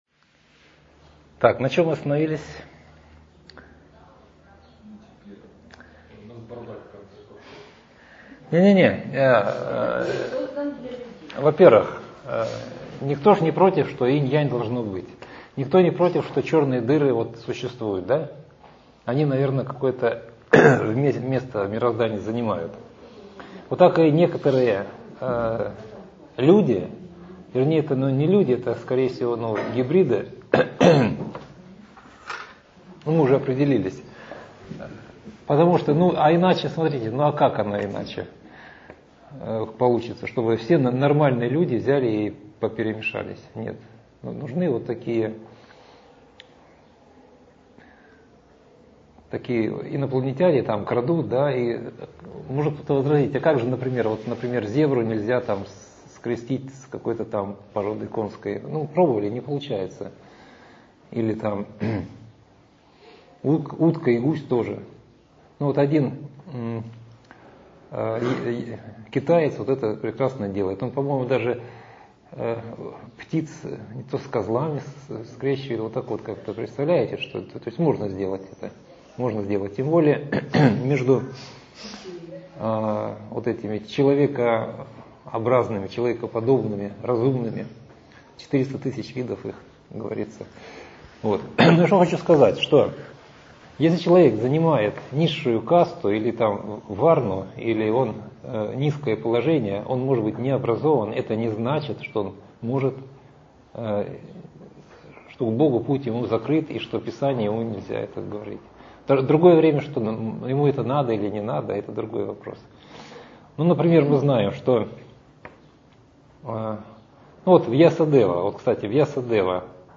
Скачать лекцию: Прививка - часть 1 Скачать лекцию: Прививка - часть 2 2.